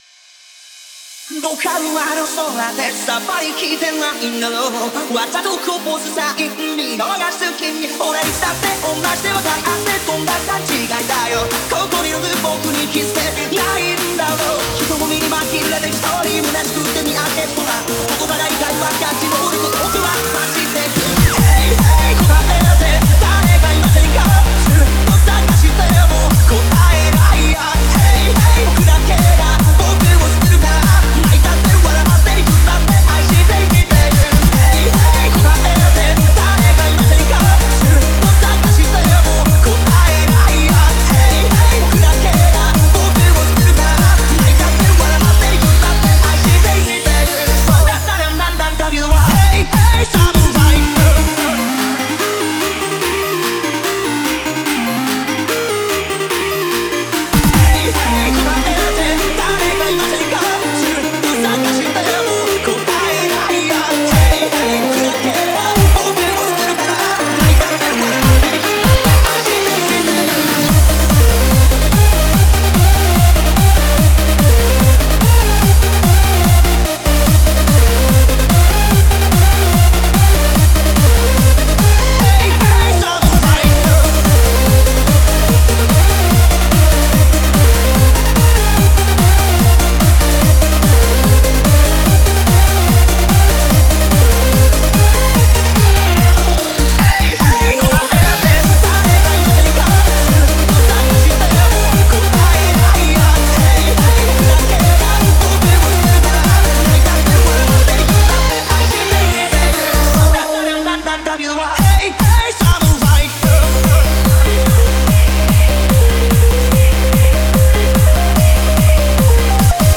Back to classic hands up :)